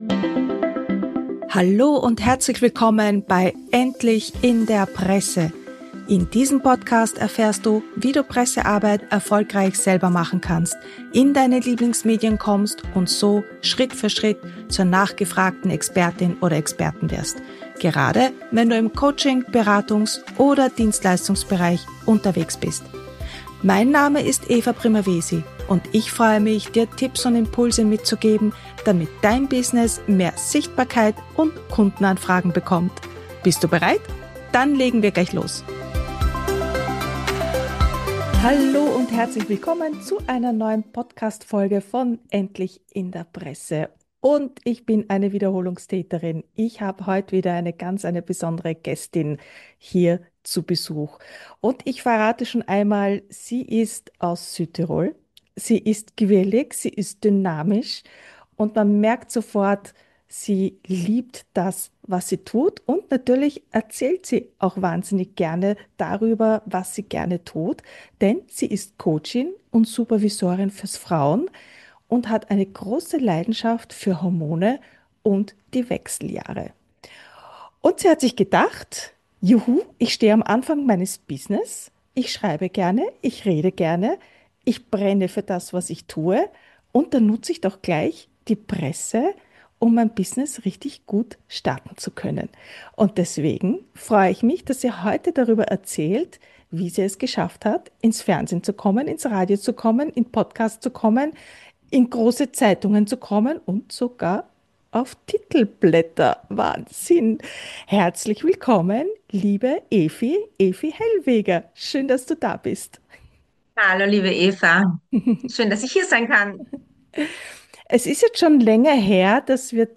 Was Medienpräsenz mit ihrem Selbstbewusstsein, ihrer Positionierung und ihrem Business gemacht hat. Und warum ihr die Ideen für PR-Themen auch in Zukunft garantiert nie ausgehen werden. Hör gleich in das Interview rein und hol dir ehrliche Einblicke, Motivation und Aha-Momente aus der Praxis.